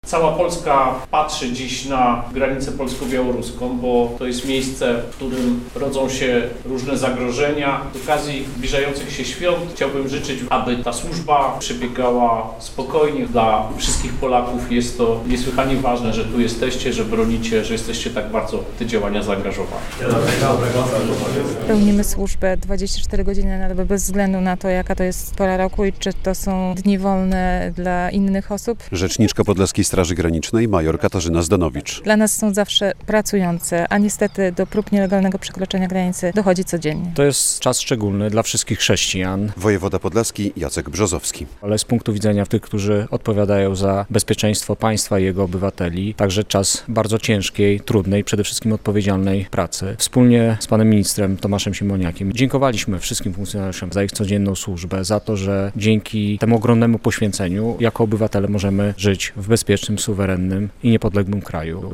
Wizyta Tomasza Siemoniaka w Bobrownikach - relacja